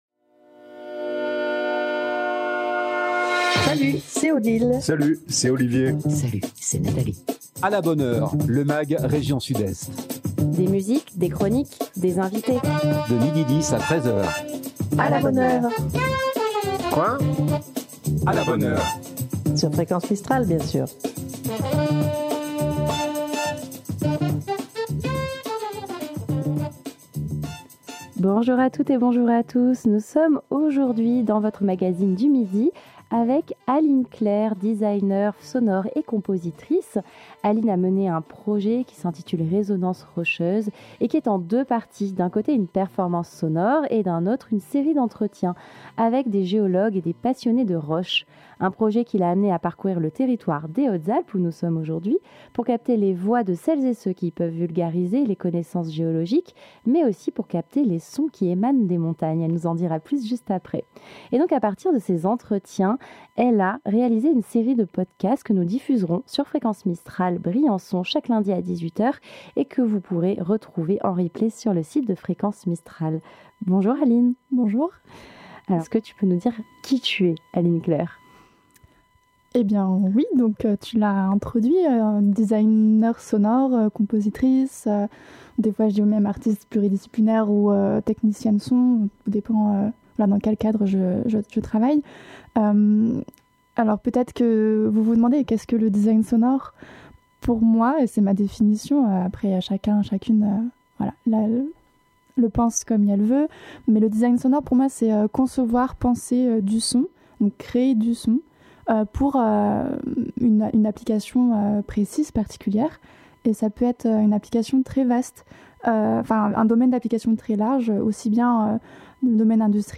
des invité.e.s en direct